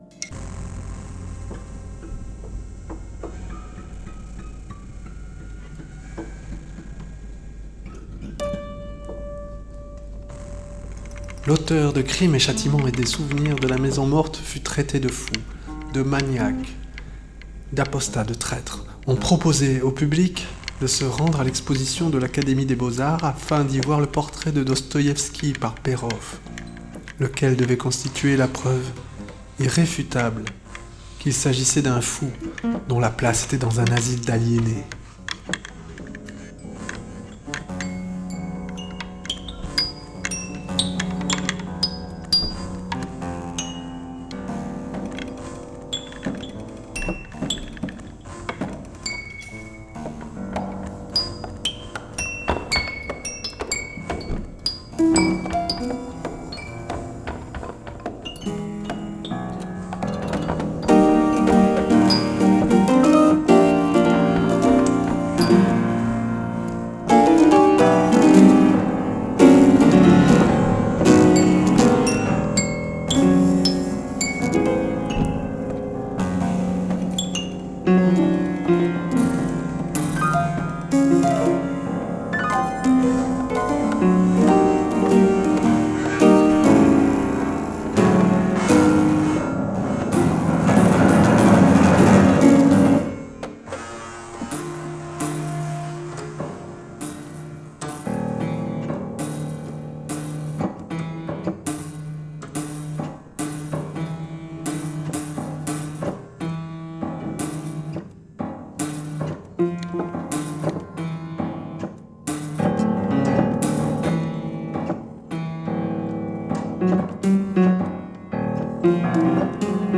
Dostofou  (extrait concert Guillotine, jan 06)